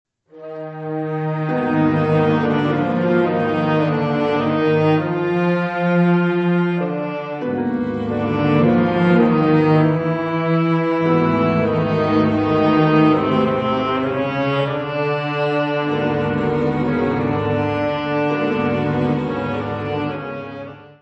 Andante molto moderato.